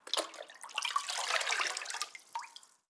SFX_GettingWater_02.wav